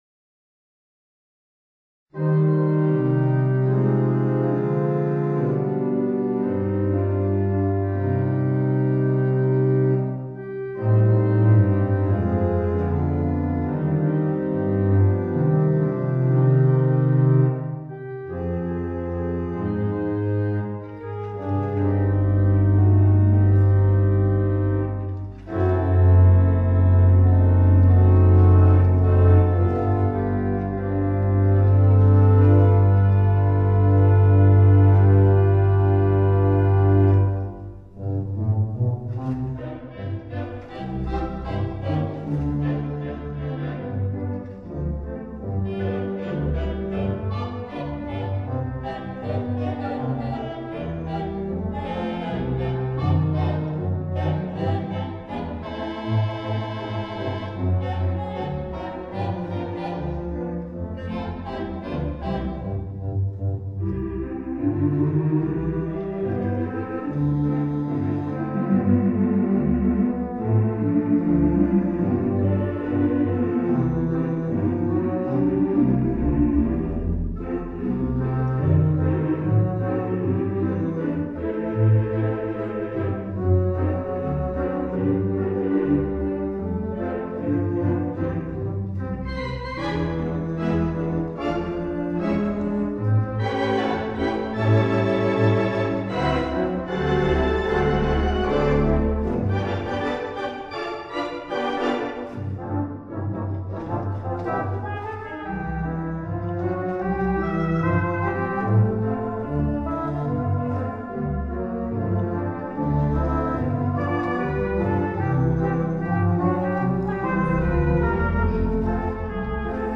The Mighty WurliTzer on the stage
Big Band Sounds